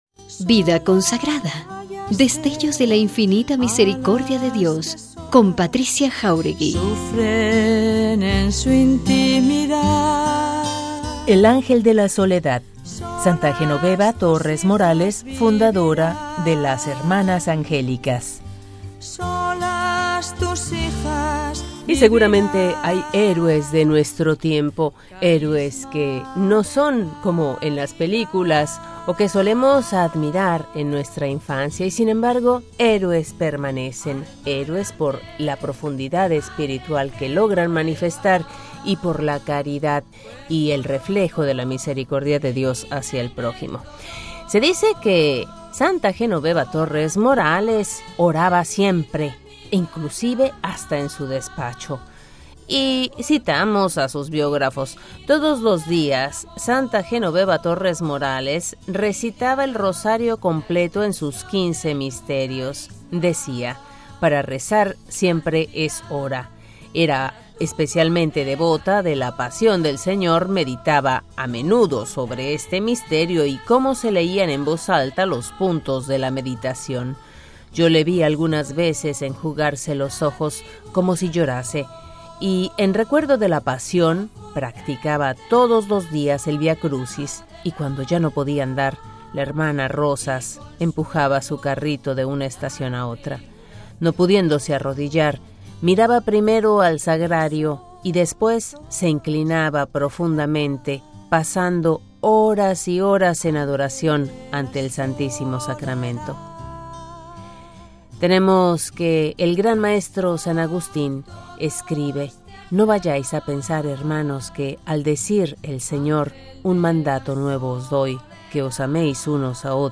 Coros de las Madres Angélicas.